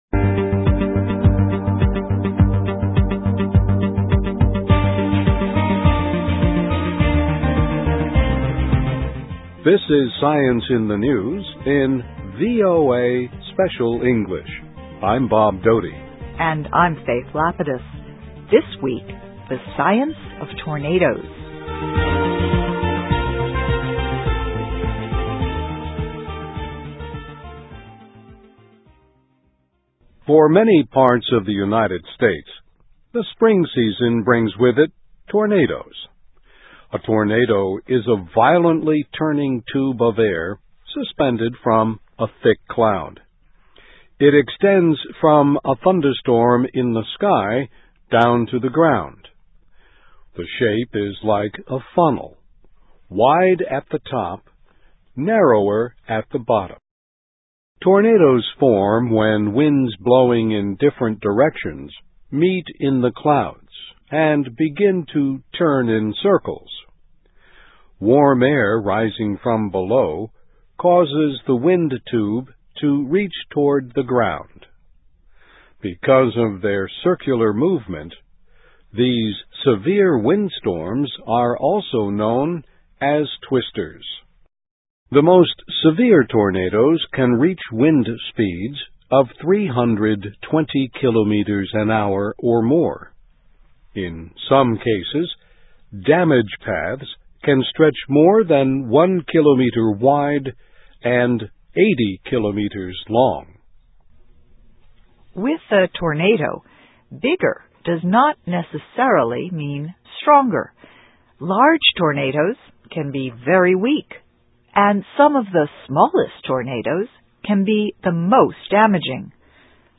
Weather: Tornado Science, in a Land With Plenty of Experience (VOA Special English 2006-05-22)